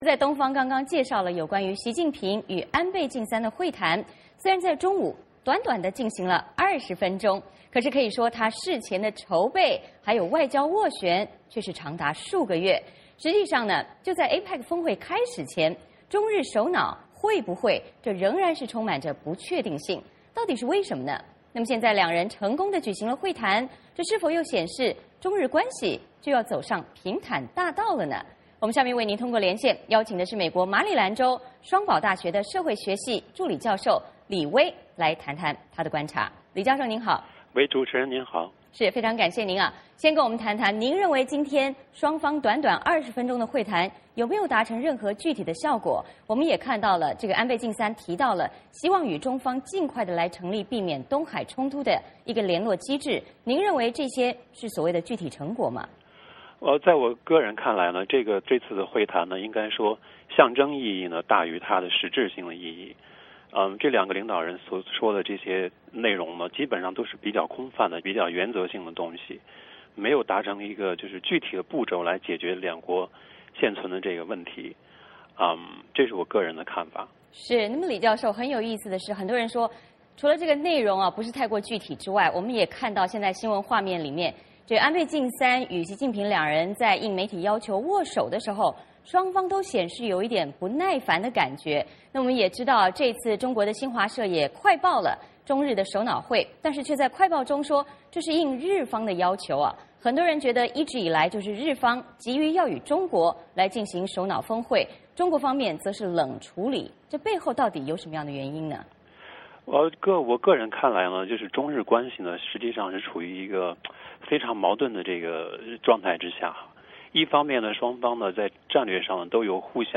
VOA连线：习近平会安倍，中日改善关系跨出第一步